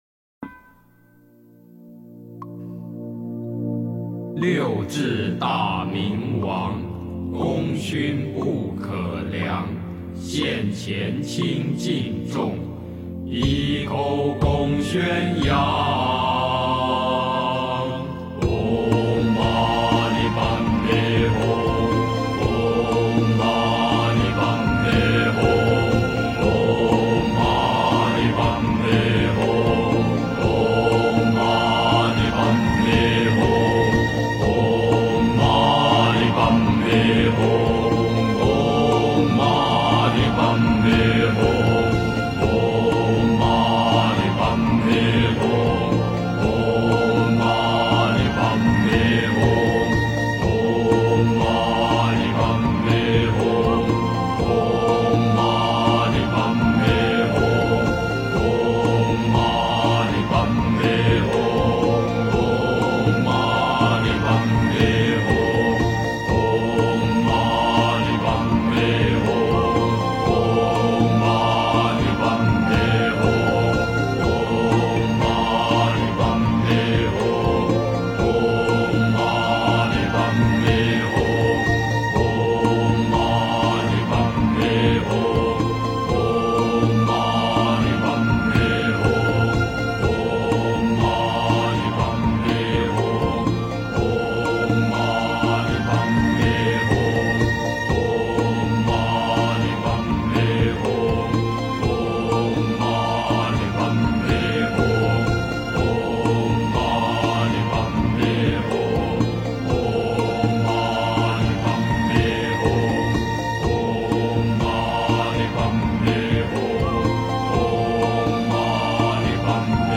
唵嘛呢叭弥吽音乐篇 诵经 唵嘛呢叭弥吽音乐篇--如是我闻 点我： 标签: 佛音 诵经 佛教音乐 返回列表 上一篇： 濯净心灵 下一篇： 拜愿 相关文章 释迦牟尼佛圣号--佛典艺术工作坊 释迦牟尼佛圣号--佛典艺术工作坊...